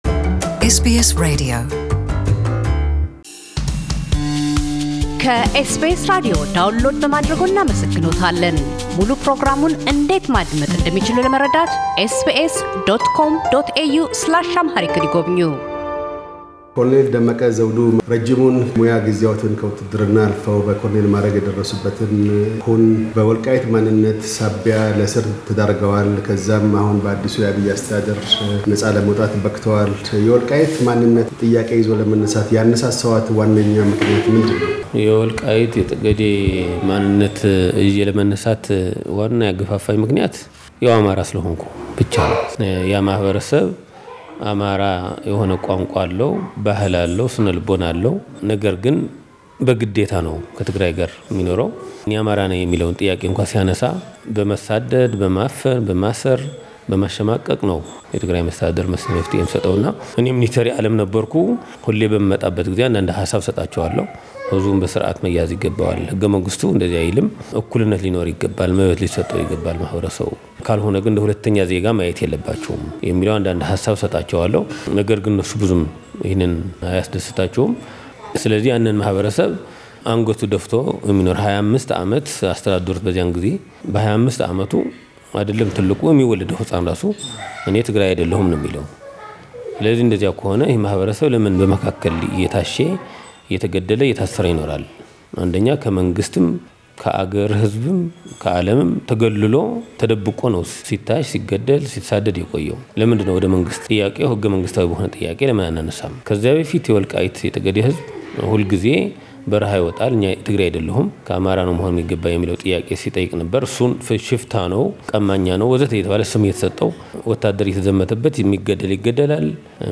ስለምን የወልቃይትና ጠገዴን የማንነት ጥያቄ ይዘው እንደተነሱ፣ ዘርን ሳይሆን ስነልቦንና ታሪክን መሰረት ያደረገ የአማራ ማንነት ማቆም እንደሚያሻና ኢትዮጵያ ውስጥ ተከስቶ ያለውን ለውጥ ሁሉም ኢትዮጵያዊ ሊጠብቀው እንደሚገባ ያሳስባሉ። ቃለ ምልልሳችን የተካሄደው በወርሃ መስከረም ጎንደር ከተማ ከሚገኘው መኖሪያ ቤታቸው ነው።